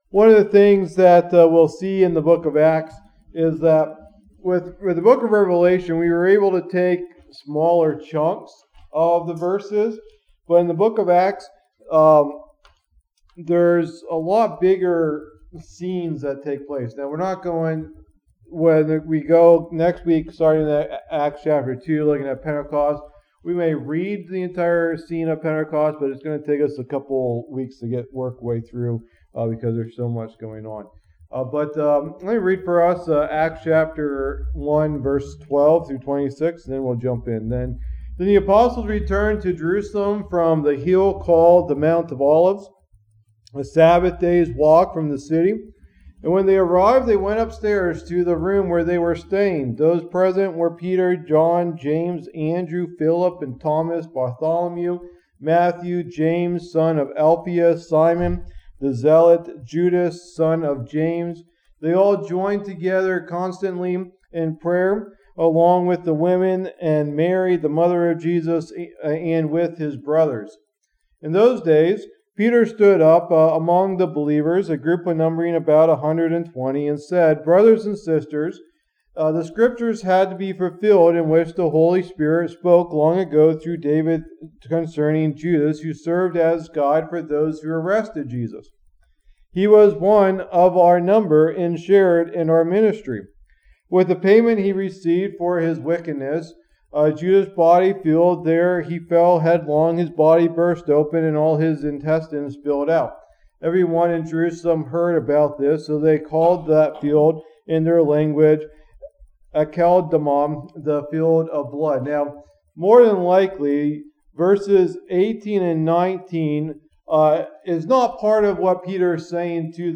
Teaching #3 of the "Book of Acts" Study